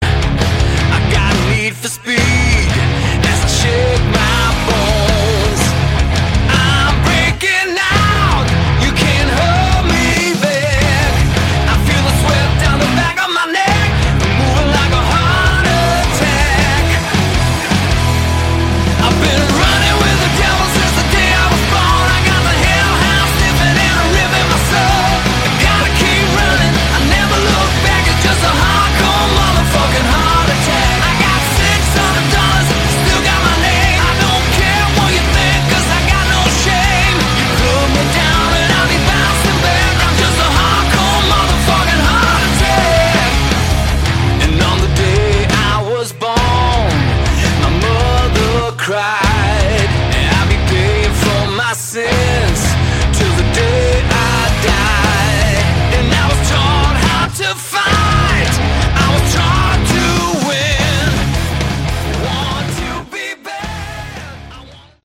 Category: Hard Rock
vocals, guitars
bass
drums, backing vocals